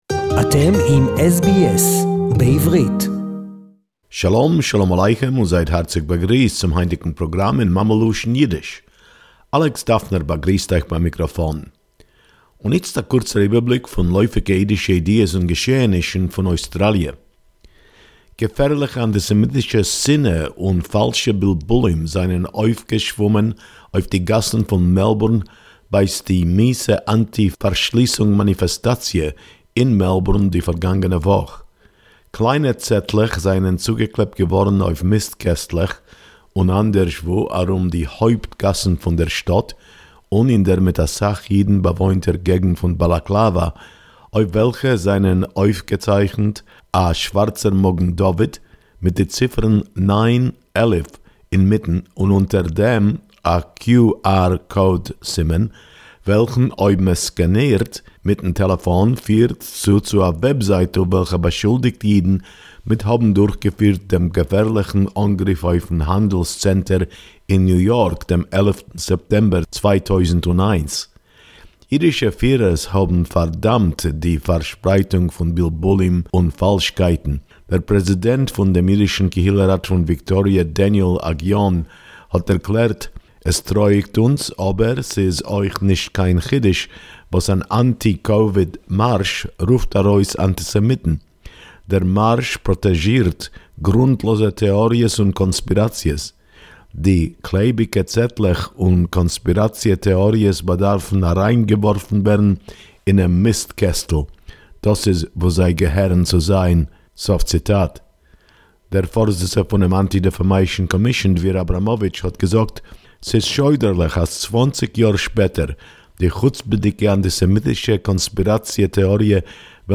SBS YIDDISH SEGMENT: 29.08.2021